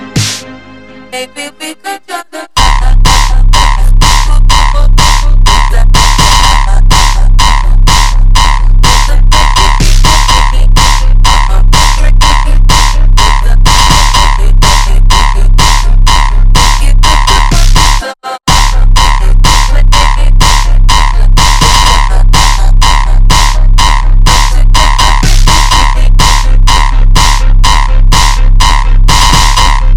Electronic Electronica
Жанр: Электроника